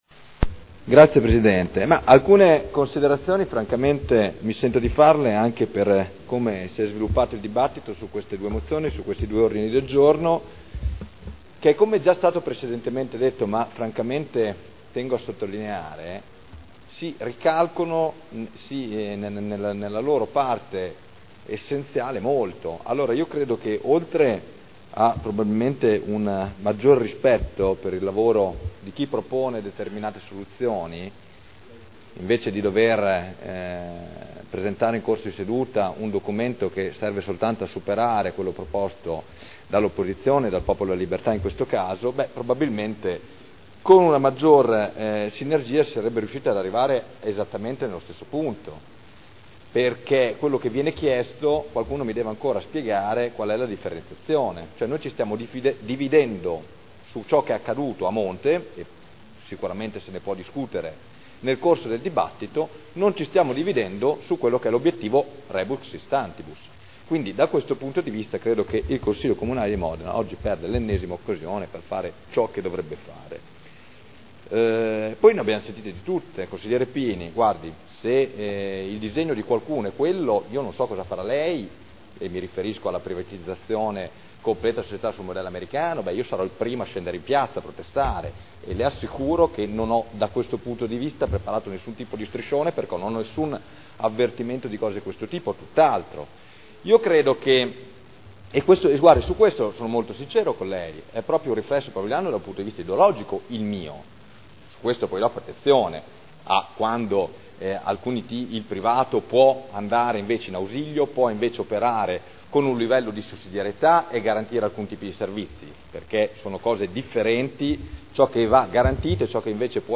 Seduta del 12/09/2011. Dibattito su Ordini del Giorno relativi all'introduzione del ticket sanitario.